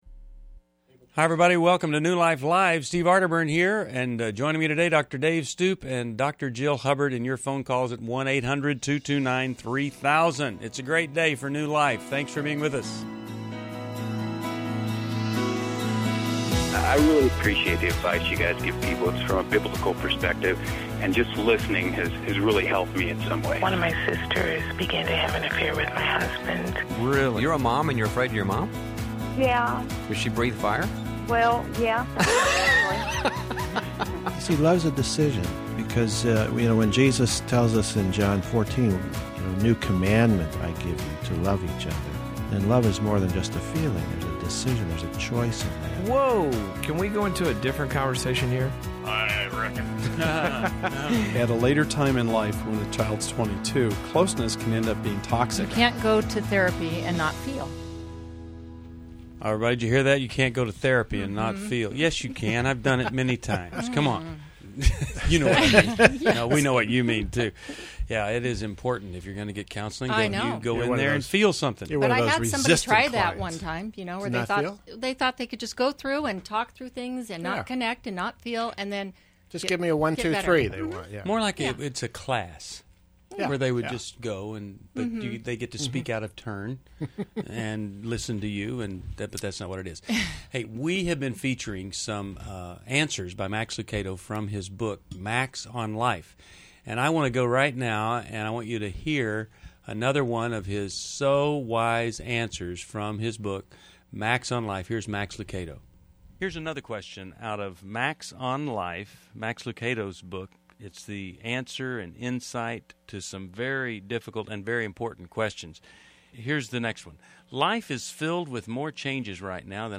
Explore tough relationship questions on New Life Live: April 27, 2011, as hosts tackle issues of Asperger's, marriage, parenting, and mental health.